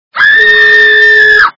Голос - Выразительный Визг Очень Сильно Напуганой Девушки Звук Звуки Голос - Выразительный Визг Очень Сильно Напуганой Девушки
» Звуки » звуки для СМС » Голос - Выразительный Визг Очень Сильно Напуганой Девушки
При прослушивании Голос - Выразительный Визг Очень Сильно Напуганой Девушки качество понижено и присутствуют гудки.